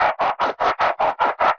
Index of /musicradar/rhythmic-inspiration-samples/150bpm